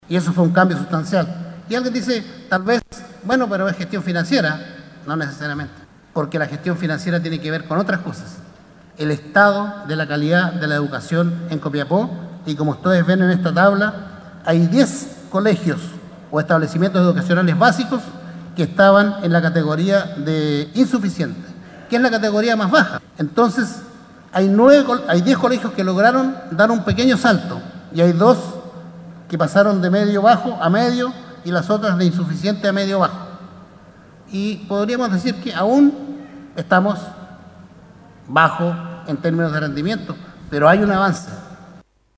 La tarde de este jueves se realizó en el Salón Alicanto, la Cuenta Pública Gestión 2018 del alcalde Marcos López, al mando de la Municipalidad de Copiapó.